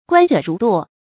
觀者如垛 注音： ㄍㄨㄢ ㄓㄜˇ ㄖㄨˊ ㄉㄨㄛˇ 讀音讀法： 意思解釋： 見「觀者如堵」。